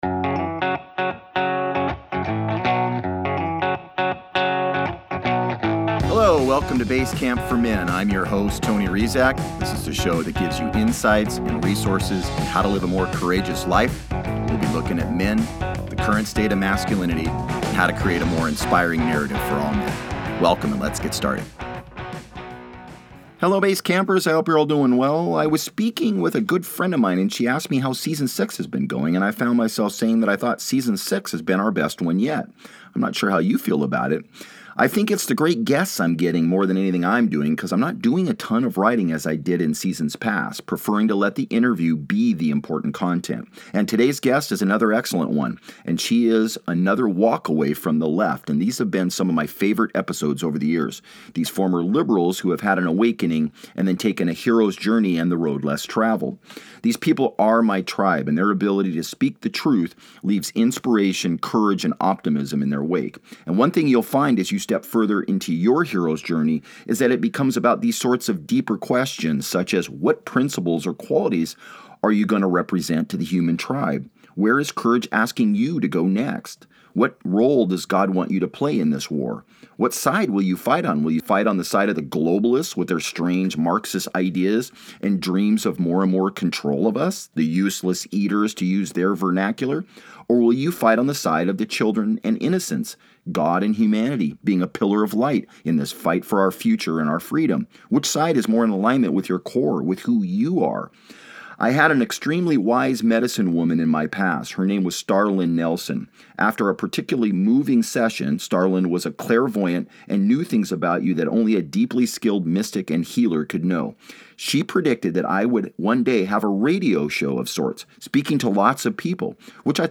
Preferring to let the interview BE the important content.